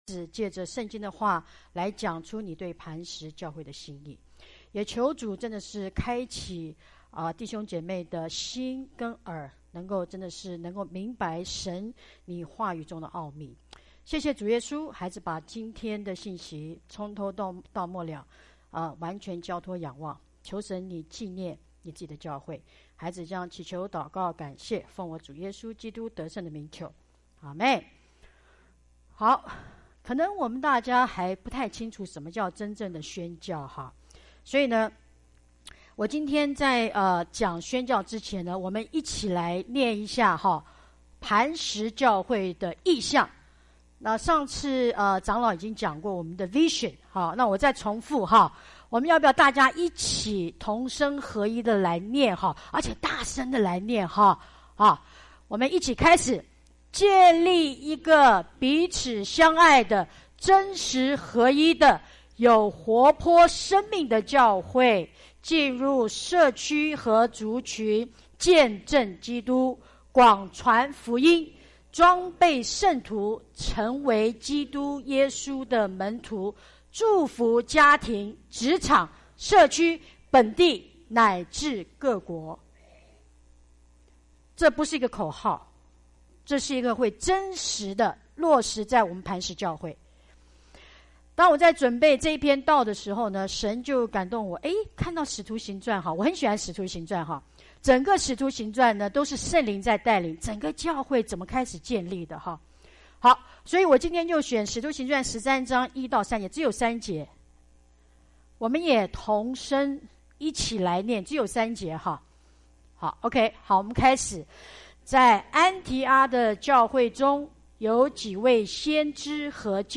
傳道 應用經文: 使徒行傳 13:1-3